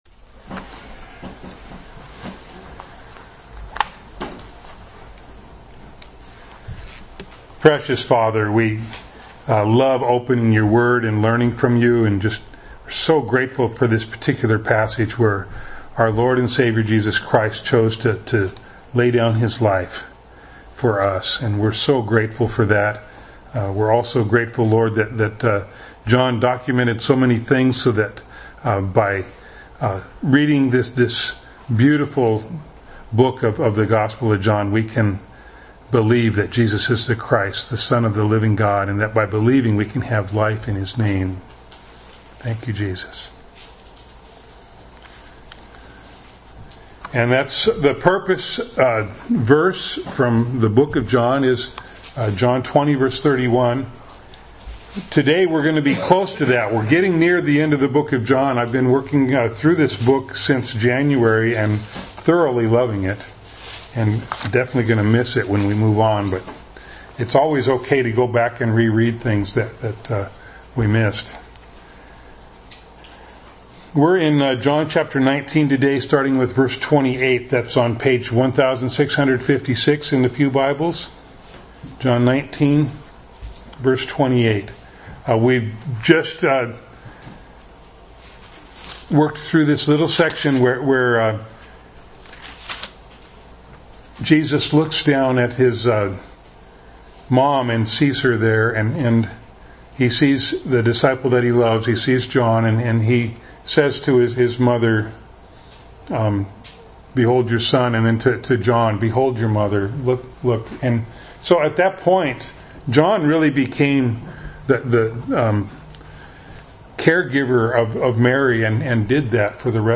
John 19:28-42 Service Type: Sunday Morning Bible Text